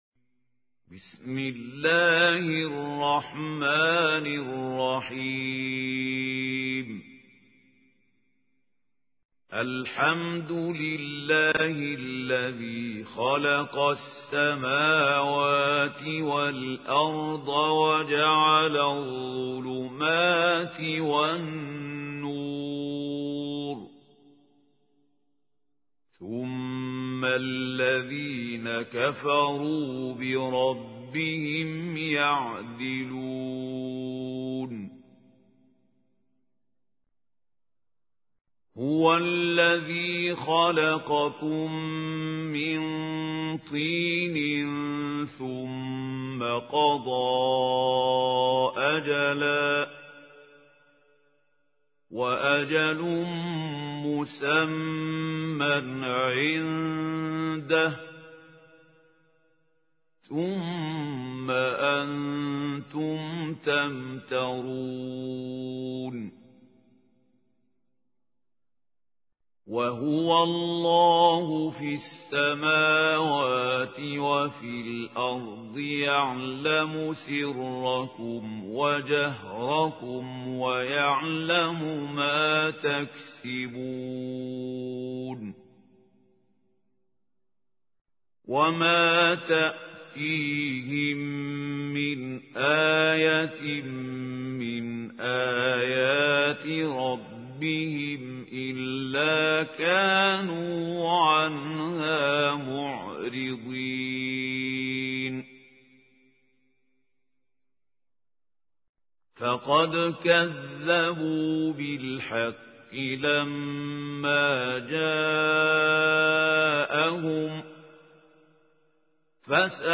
القارئ: الشيخ خليل الحصري
القرآن الكريم - سورة الانعام - الشيخ خليل الحصري